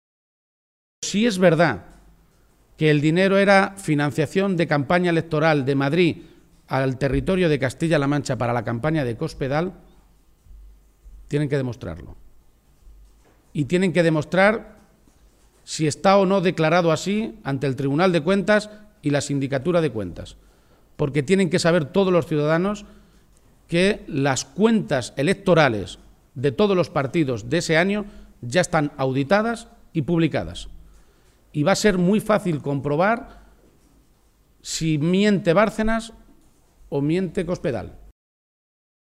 Momento de la rueda de prensa